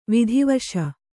♪ vidhi vaśa